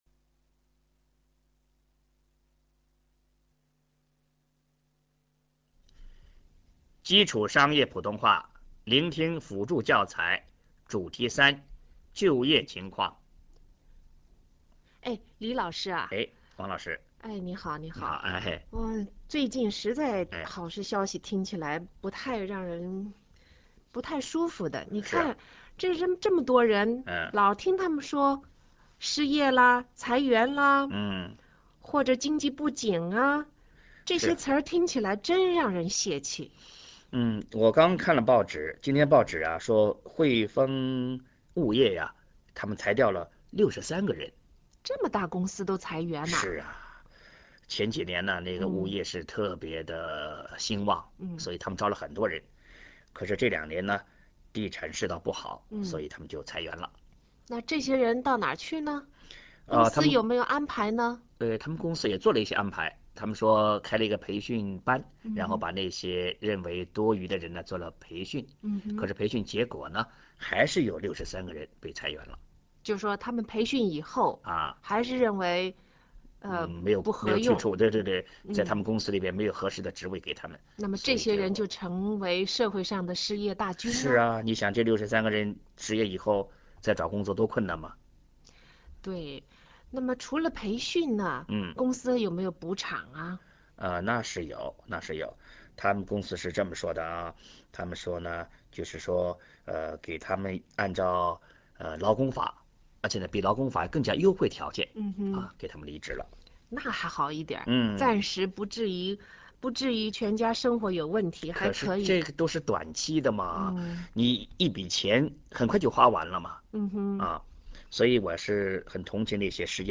基礎商業普通話聆聽輔助教材